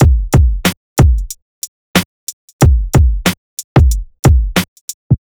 HP092BEAT2-R.wav